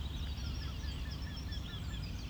Tītiņš, Jynx torquilla
StatussDzirdēta balss, saucieni